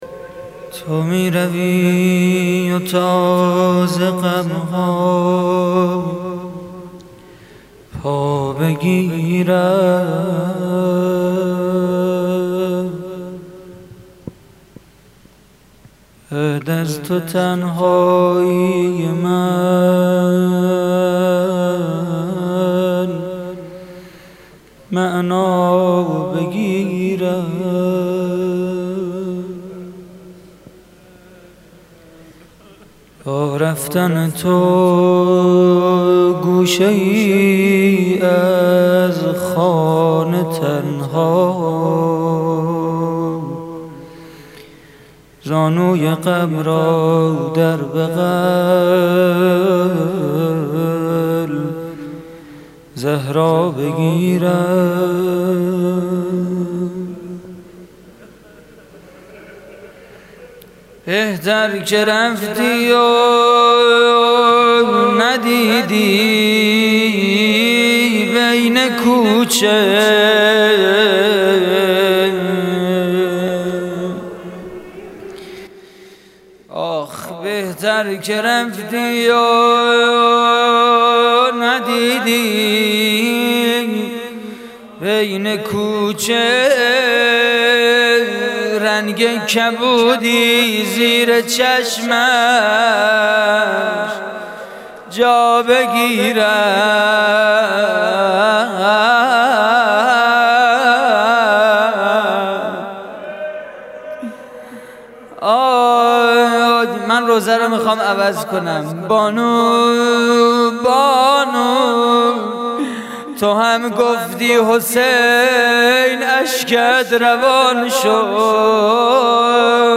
مناجات سحری ماه رمضان / هیئت الزهرا (س) دانشگاه صنعتی شریف - تیر 93
روضه: تو می‌روی و...